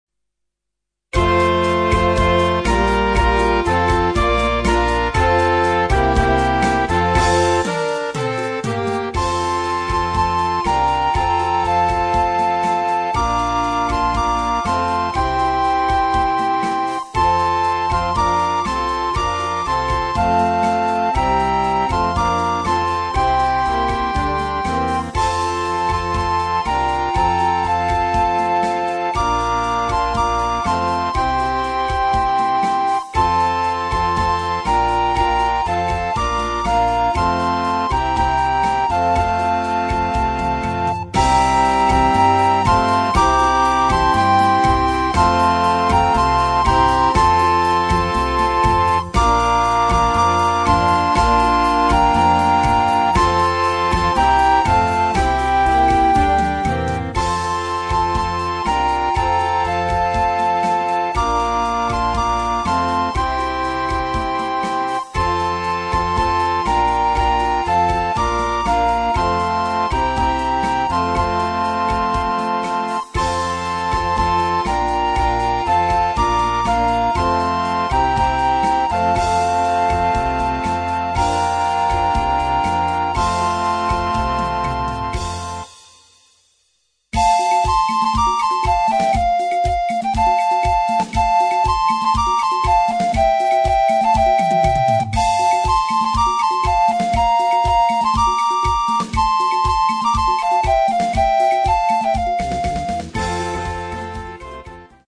Groupe de flûtes à bec avec accompagnement orchestral
Partitions pour ensemble flexible, 4-voix + percussion.